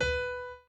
b_pianochord_v100l16-7o5b.ogg